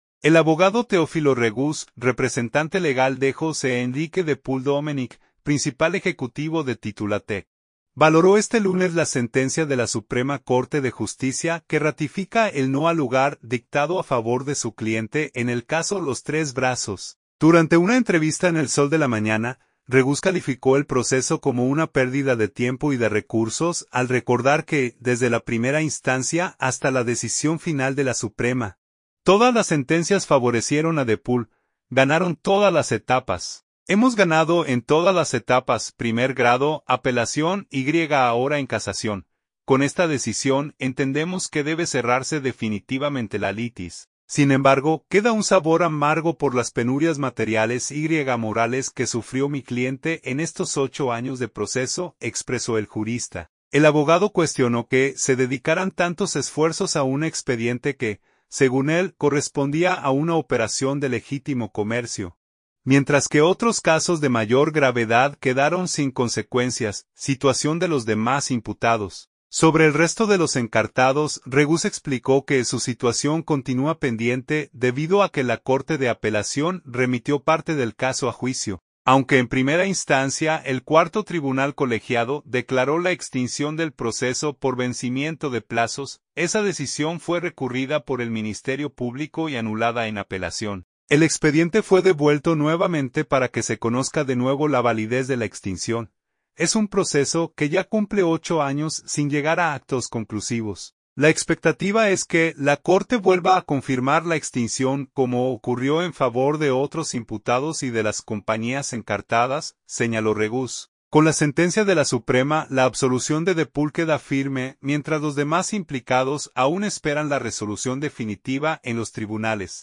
Durante una entrevista en El Sol de la Mañana